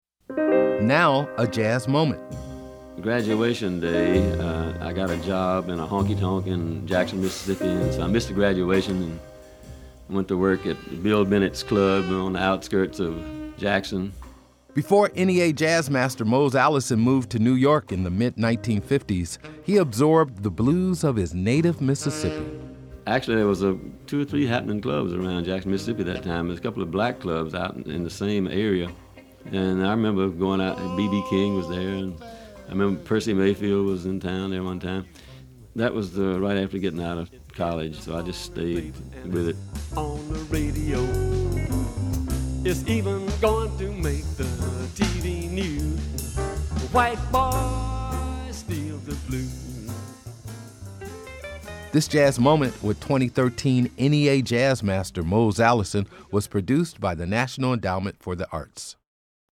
Music Credit: Excerpt of "Sittin' and Cryin" written and performed by Willie Dixon with Memphis Slim, from the CD, Willie's Blues, used courtesy of Sony Music Entertainment, and used by permission of BMG Chrysalis (BMI).